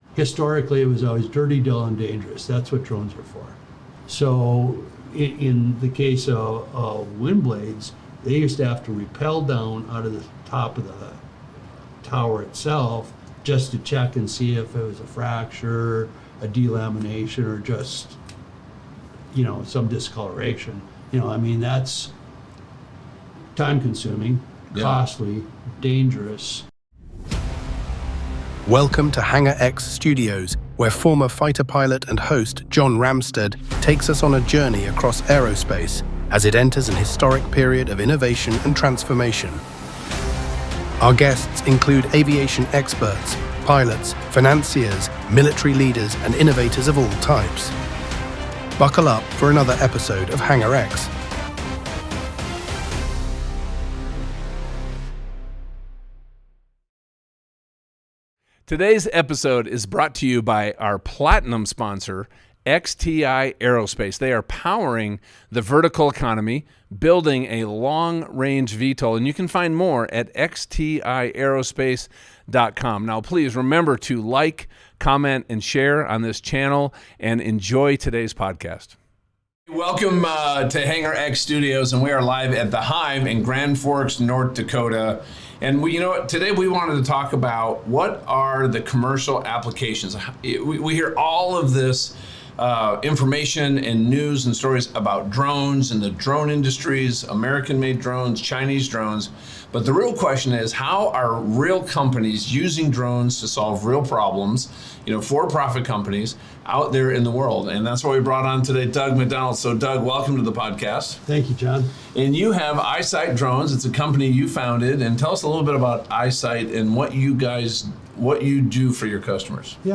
Recorded live at The Hive in Grand Forks, North Dakota, this conversation spotlights the practical side of drone deployment and the future of advanced air mobility.